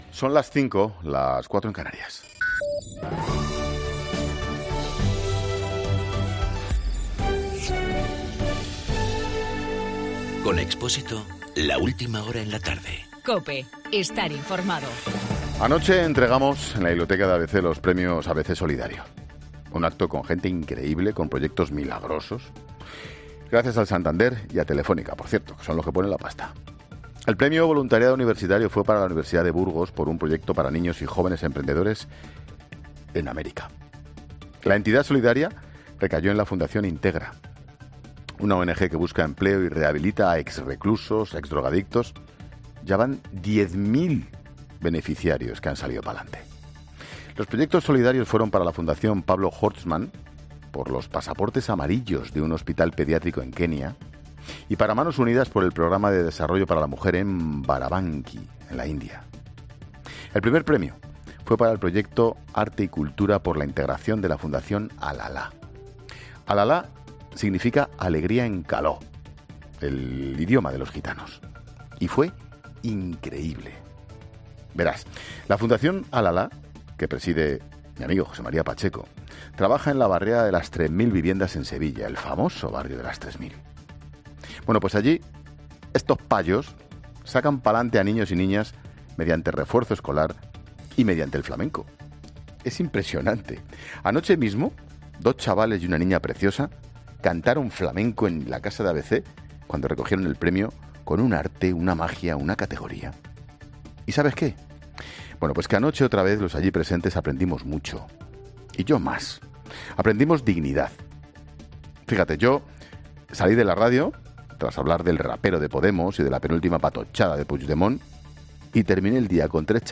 Monólogo de Expósito
Monólogo de Ángel Expósito a las 17h, un día después de los Premios ABC Solidario.